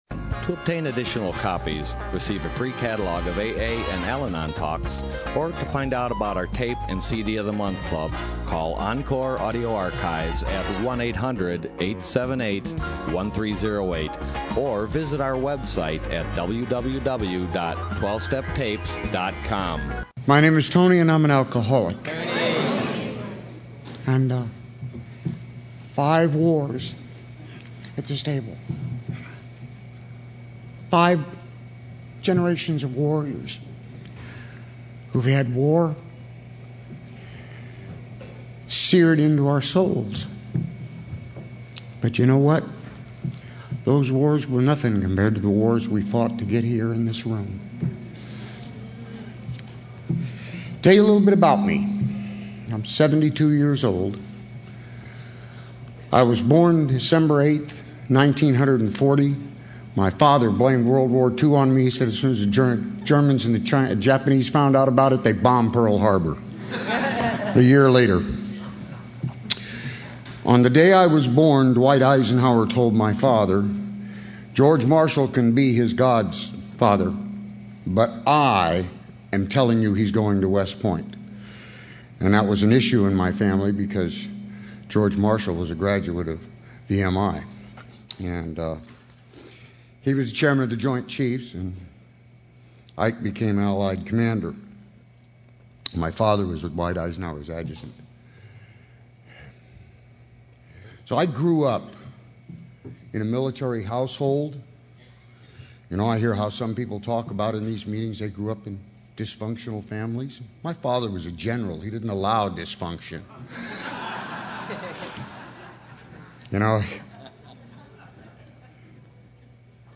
SOUTHBAY ROUNDUP 2013